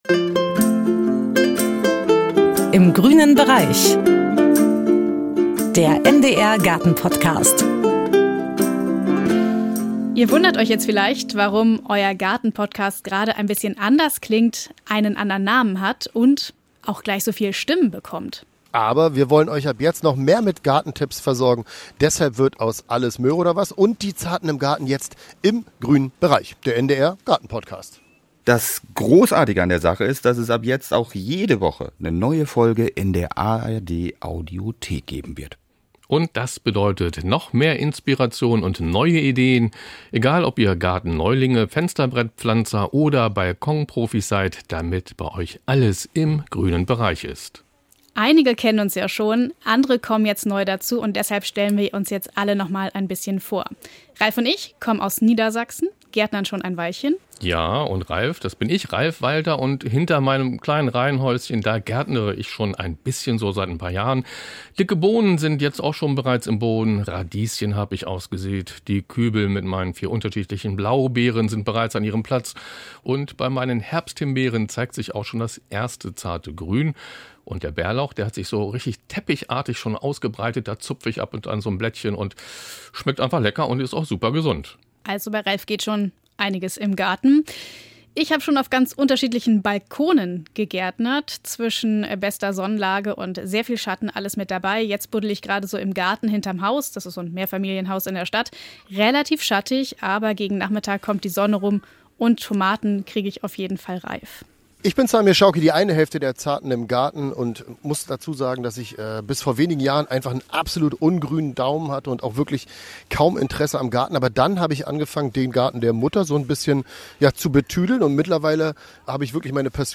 Nachrichten 08:00 Uhr - 28.03.2025